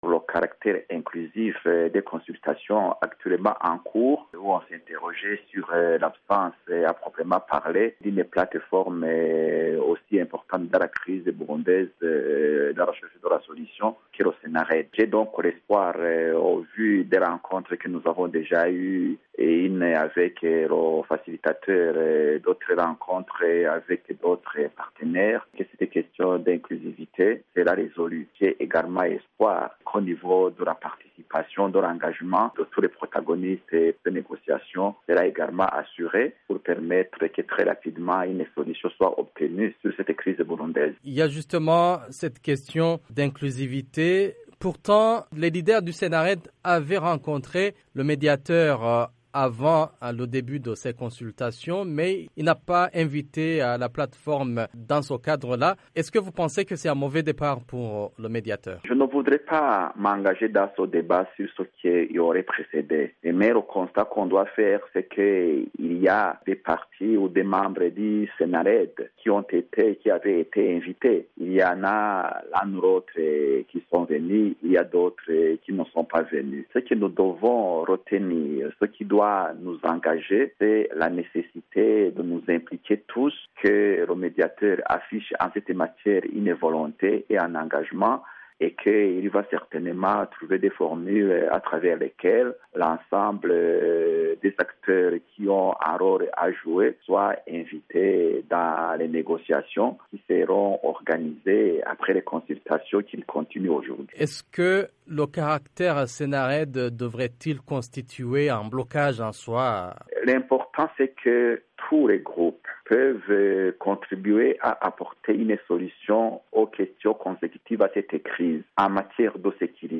Sur VOA Afrique, l’ancien président burundais Sylvestre Ntibantunganya a déclaré que "les inquiétudes ont été exprimées dans les milieux burundais mais aussi dans les milieux des partenaires du Burundi".